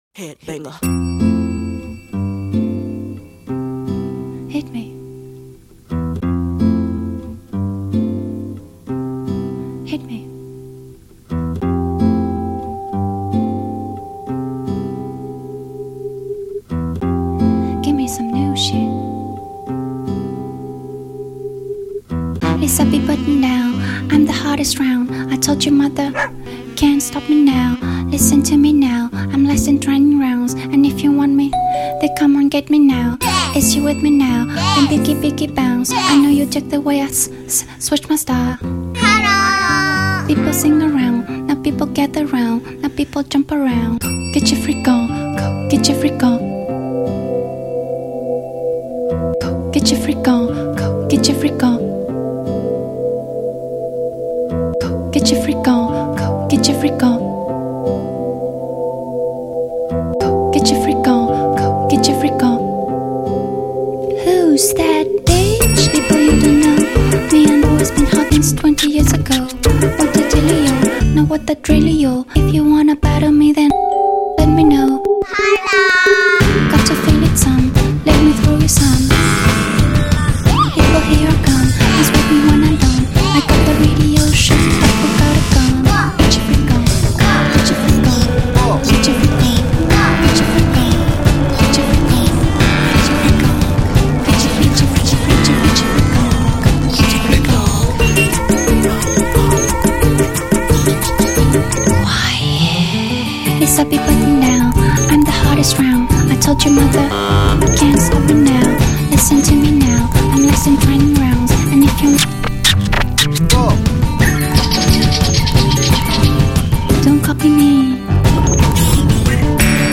↑ for reference this song needs quantizing ↑